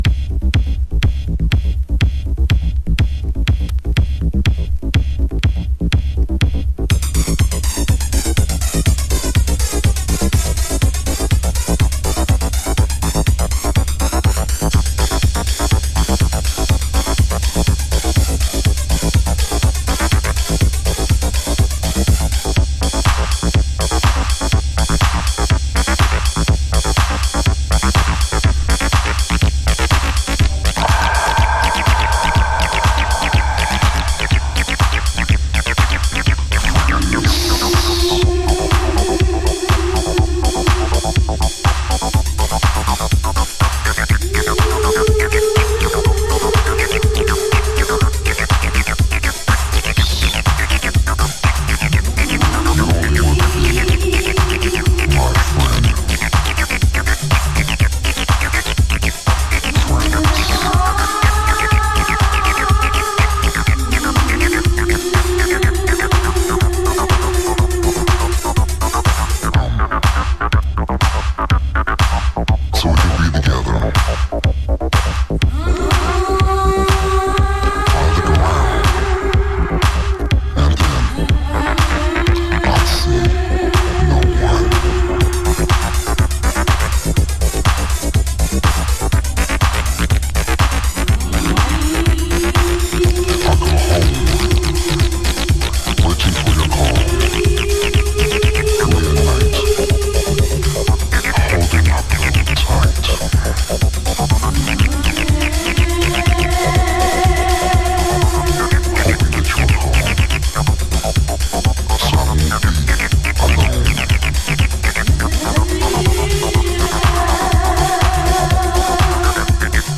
意味不明なエキゾ枠を一周した女性コーラスとピッチシフトした語りをストレートな303が暴力的にアシッドの渦に巻き込む
Unreleased Acid Version